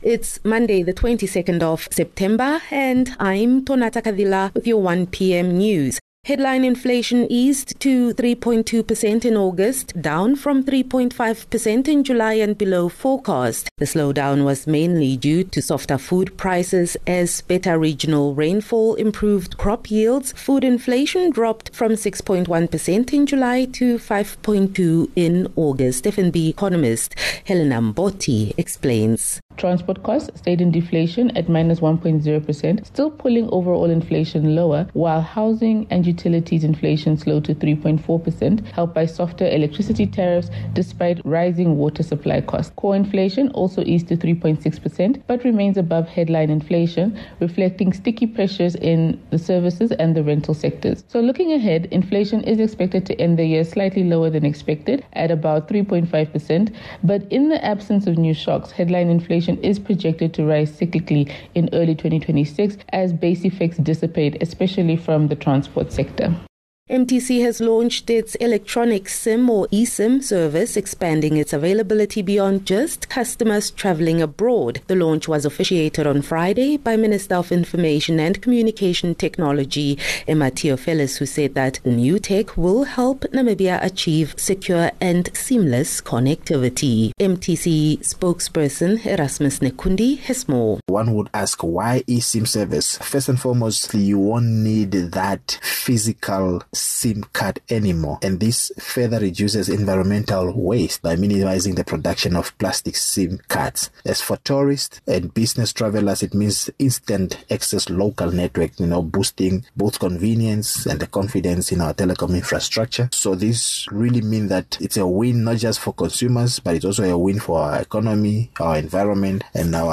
22 Sep 22 September - 1 pm news